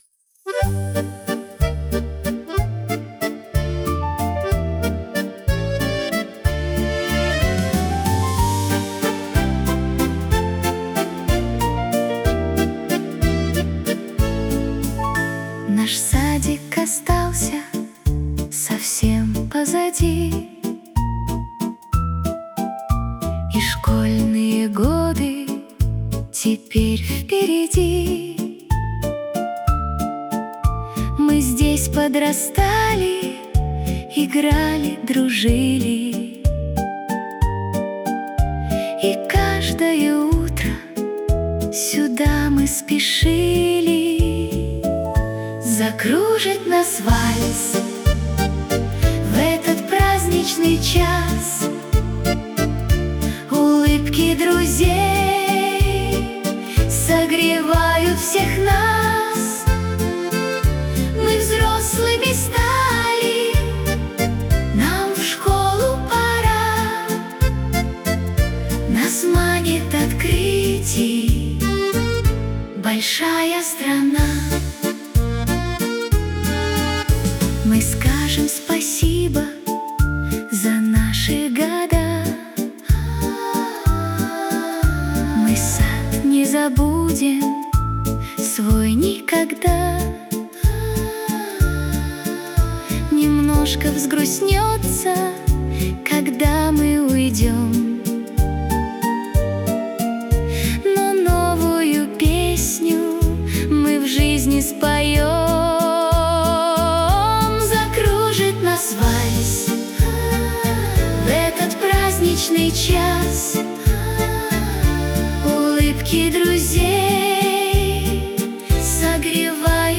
• Жанр: Детские песни
вальс выпускников